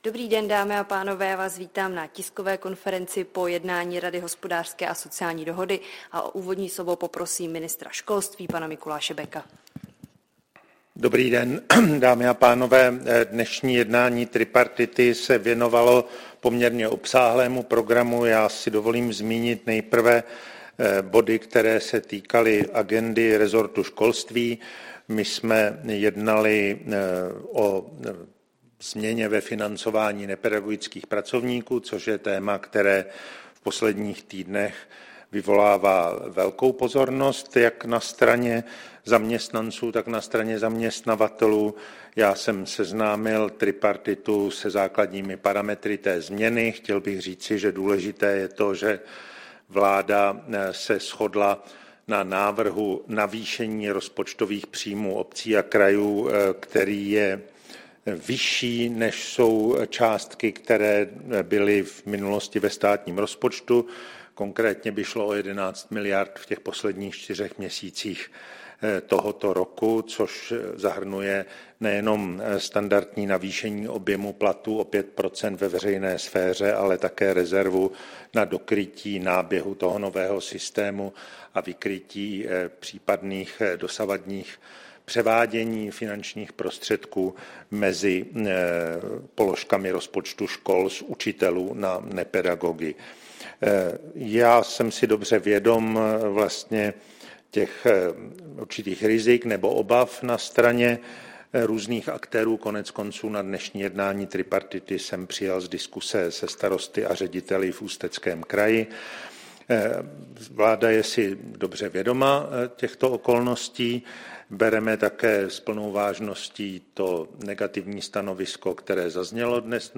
Tisková konference po 178. plenární schůzi Rady hospodářské a sociální dohody ČR